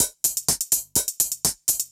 Index of /musicradar/ultimate-hihat-samples/125bpm
UHH_ElectroHatD_125-02.wav